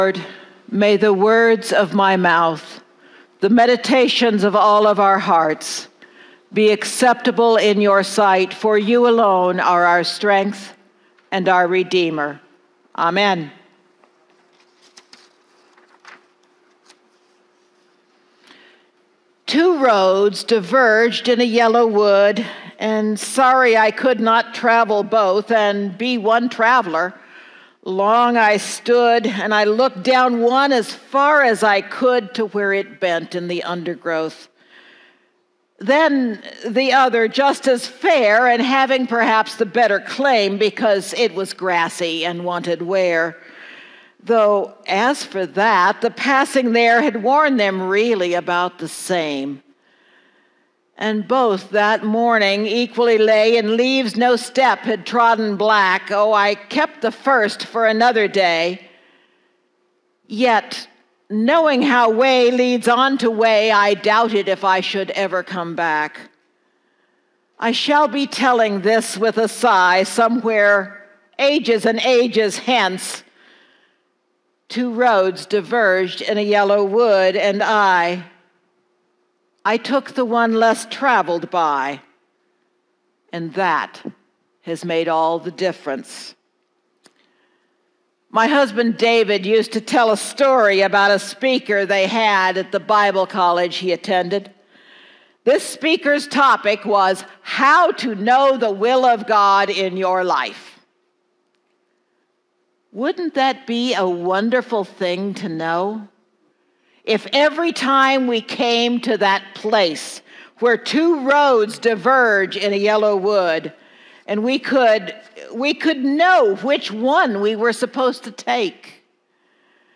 Sermons | First Presbyterian Church of Great Falls Montana